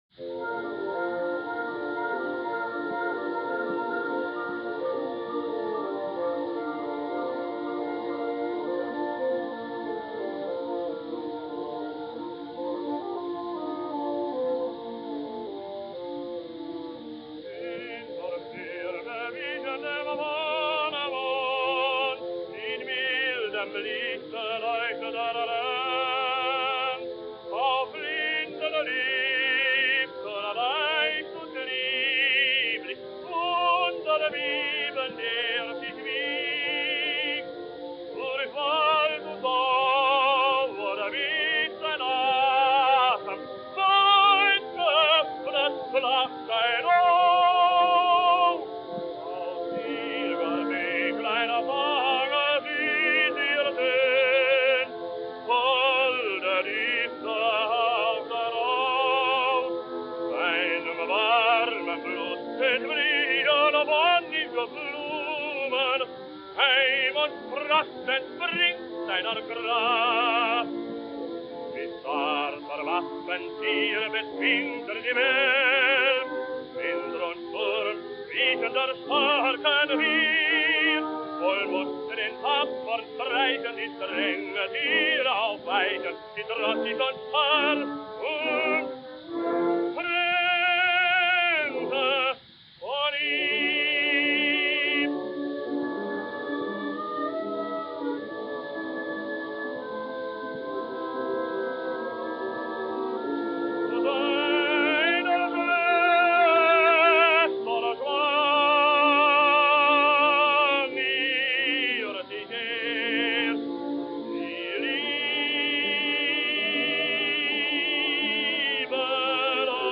Les gravacions, malgrat les precàries condicions tècniques, si que denoten una manera de cantar, d’emetre el so i d’una consistència vocal que en alguns casos no sé si serien acceptats avui en dia.
Què us sembla aquest Winterstürme cantat per Ernst Kraus l’any 1904?, tres anys abans aquest tenor alemanys va ser el Siegmund a Bayreuth, l’any següent Erik, i el 1899 Walther von Stolzing i Siegfried, rol que repetiria en els festivals de 1901,1902,1904,1906 i 1909
ernst-kraus.mp3